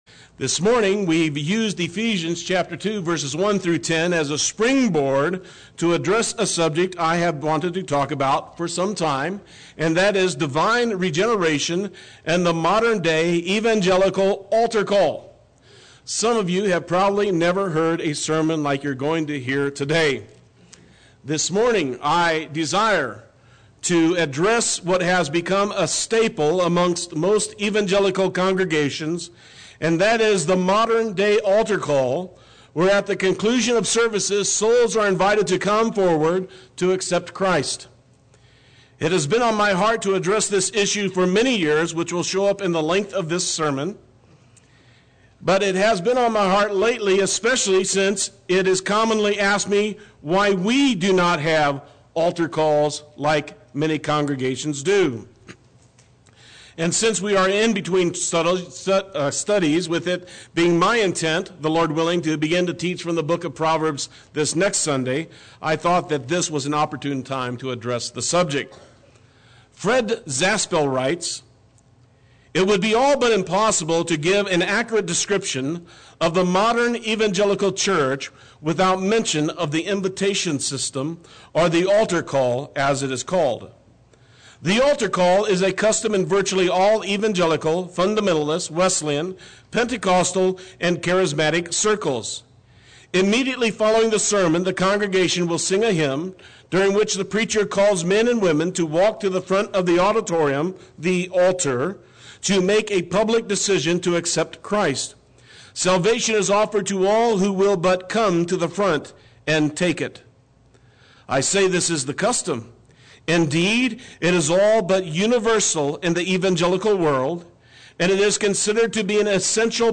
Play Sermon Get HCF Teaching Automatically.
Divine Regeneration and the Modern Day Evangelistic Altar Call Sunday Worship